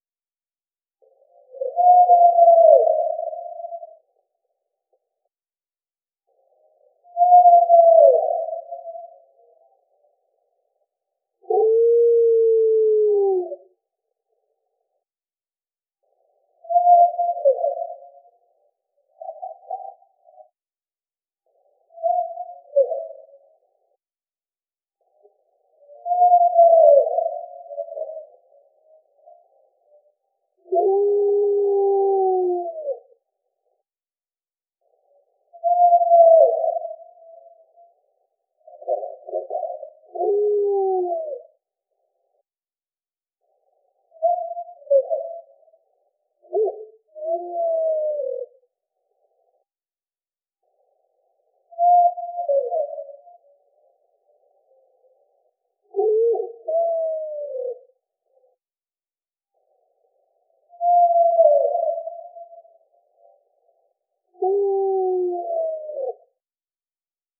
The_vocalization_exchange_at_the_settlement_area